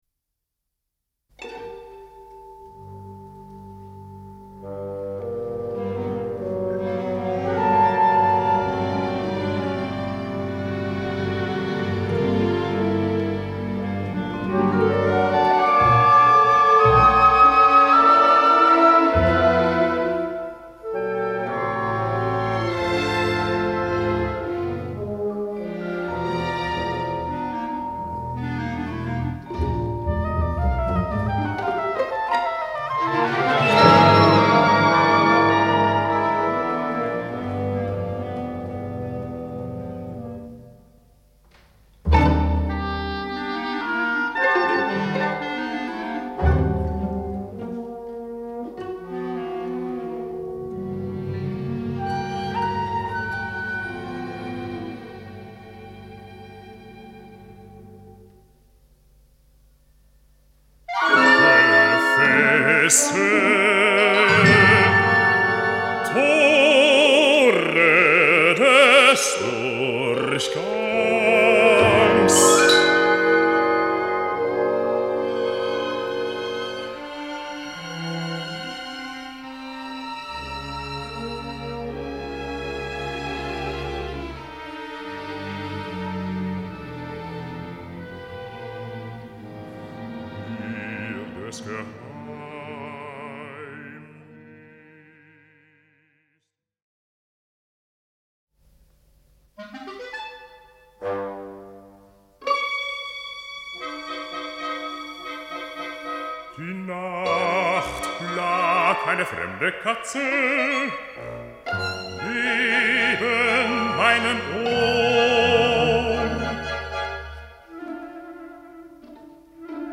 Gesang mit Orchester